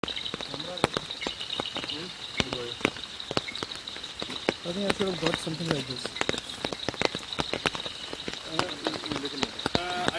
Green-Eyed Bush Frog Scientific Name: Raorchestes Chlorosomma